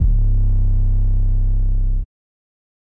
808 (OKRA).wav